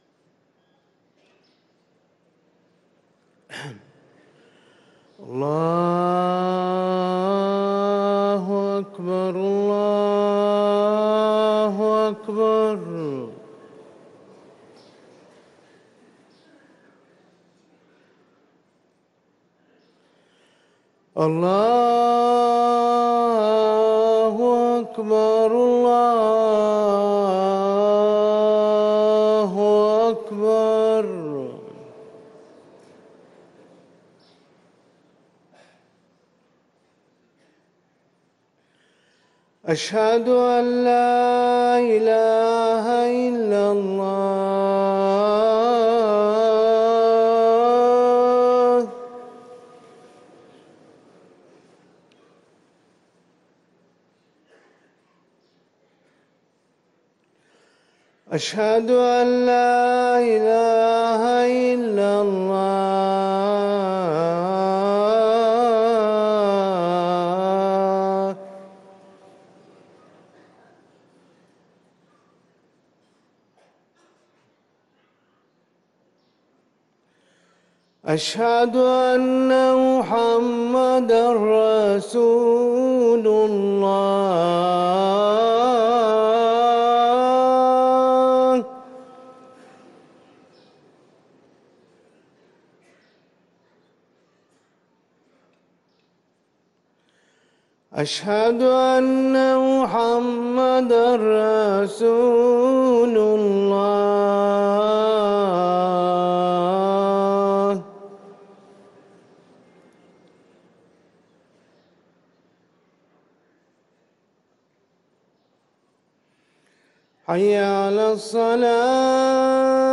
أذان الظهر